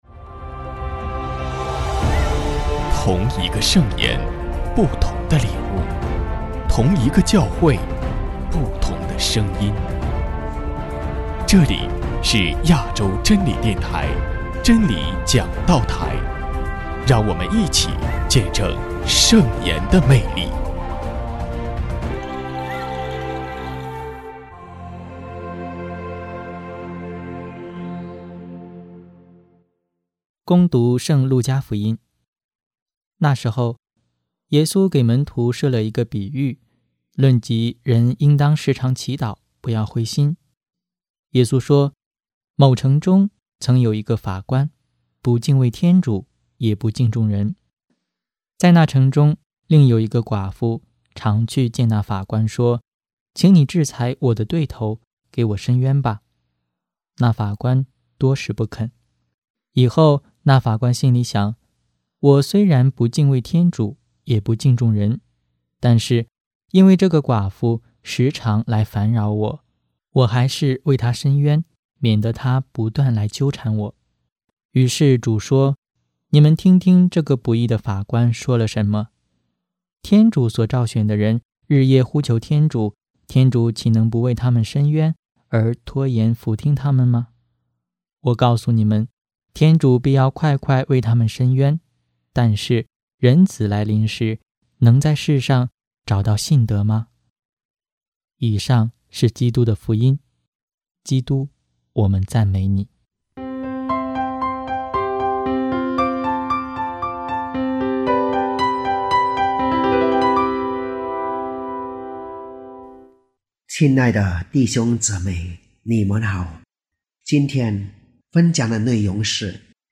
证道：